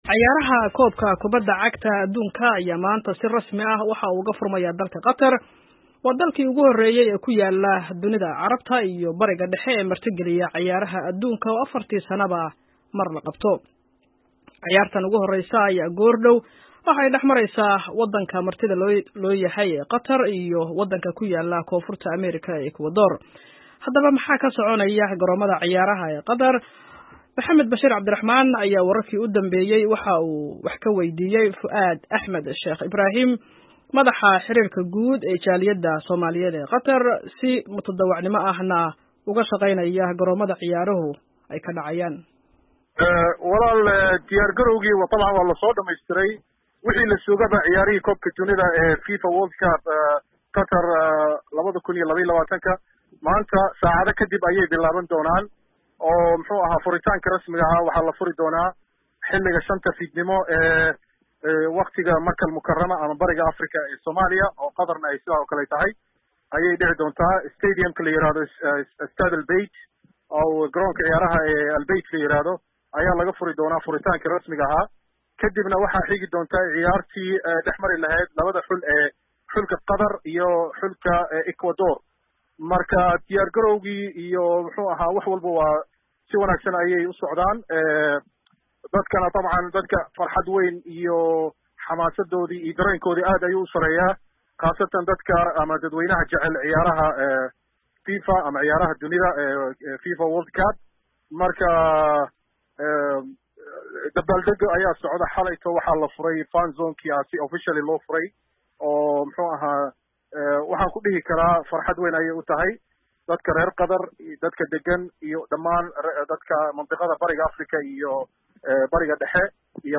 Qadar Vs Ecuador: Waa sidee jawiga garoonka Al Bayt? Wareysi